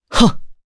Pavel-Vox_Attack1_jp_b.wav